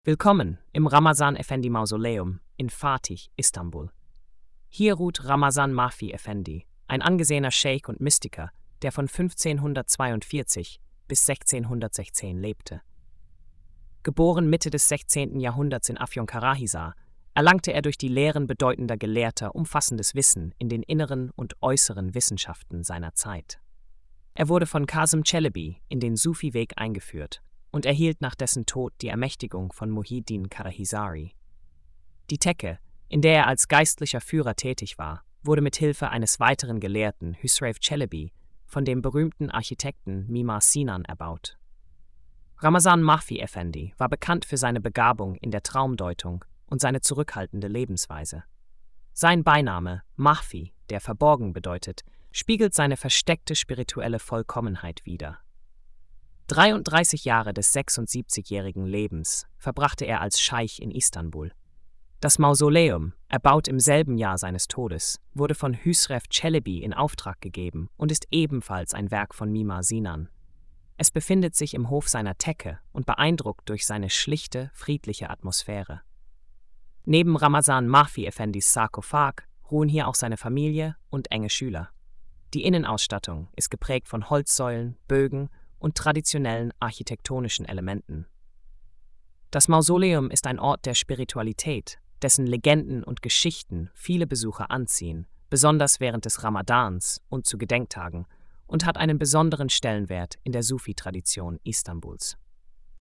Audio Erzählung: